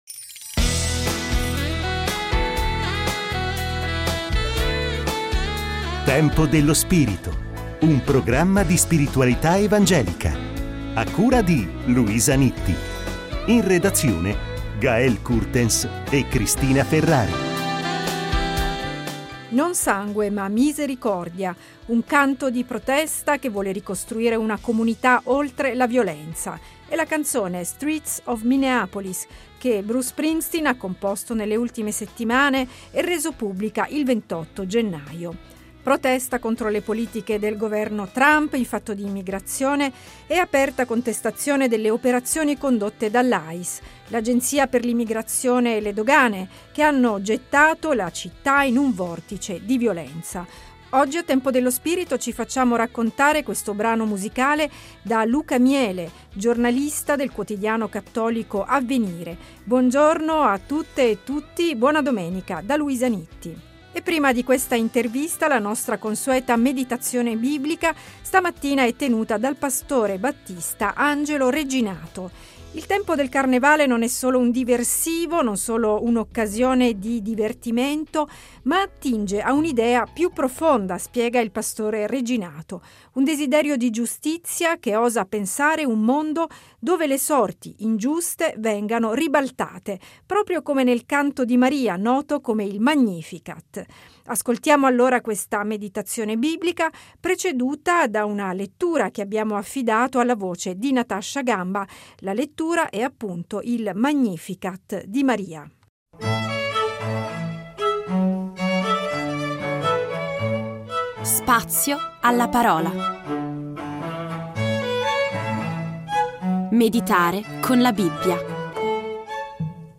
Scopri la serie Tempo dello spirito Settimanale di spiritualità evangelica.